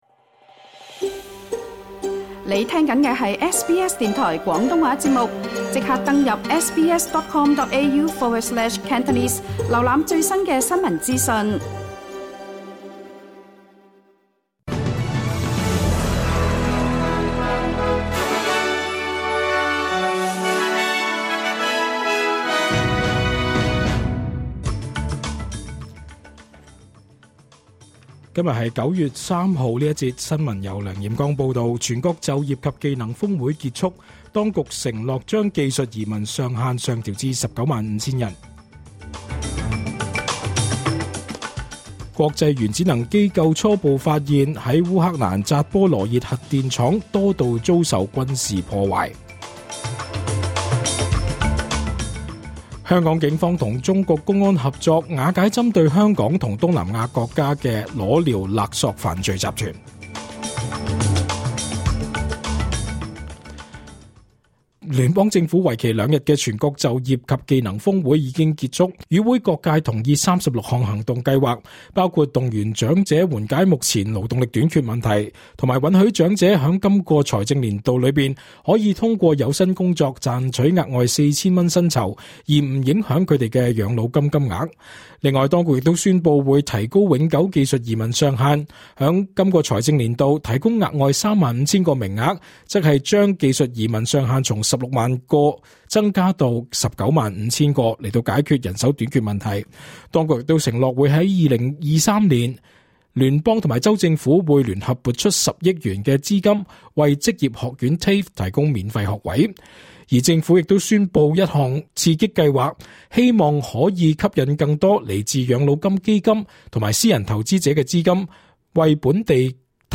SBS 廣東話新聞 (9月3日)